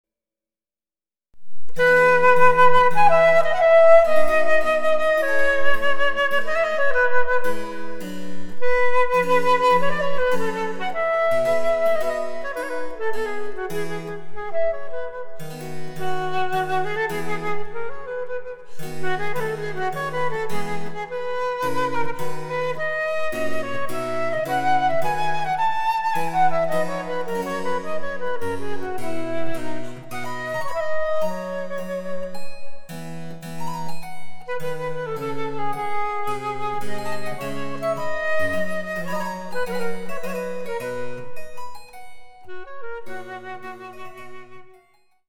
★フルート用の名曲をチェンバロ伴奏つきで演奏できる、「チェンバロ伴奏ＣＤつき楽譜」です。
(1)各楽章につきモダンピッチ(A=442Hz)の伴奏
デジタルサンプリング音源使用